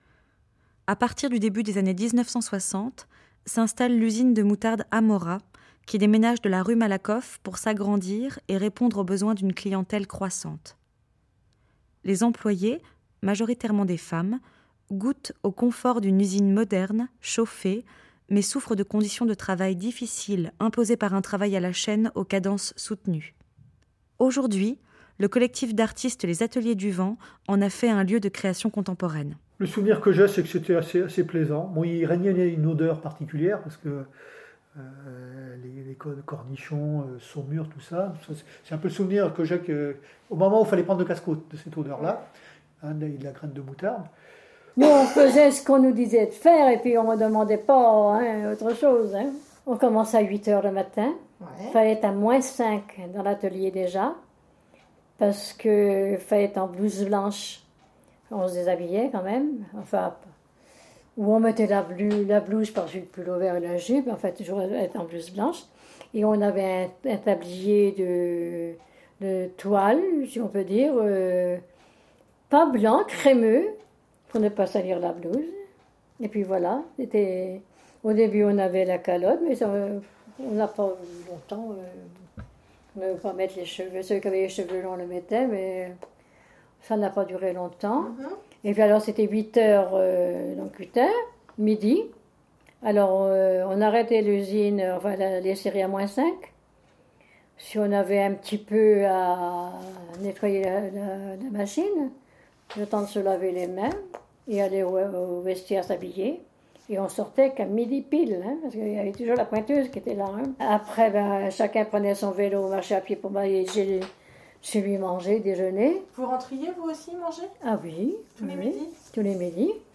Les montages sonores